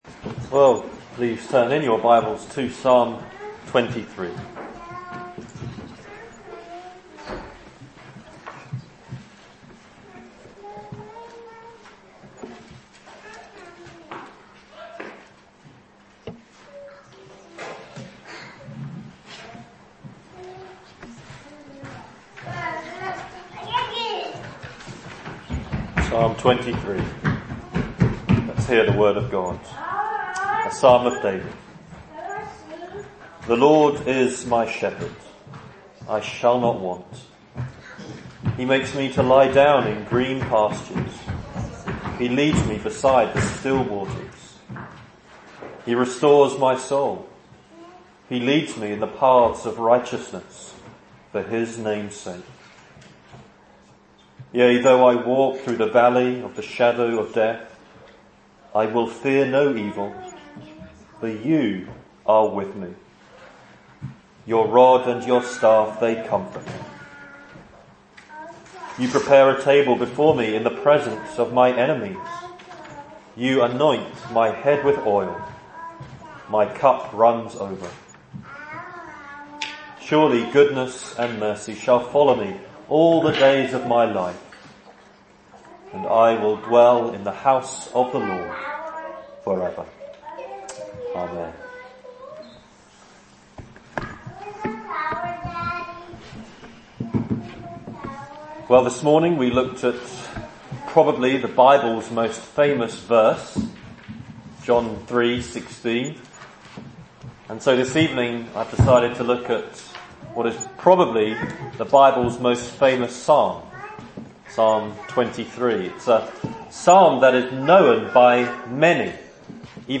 2015 Service Type: Sunday Evening Speaker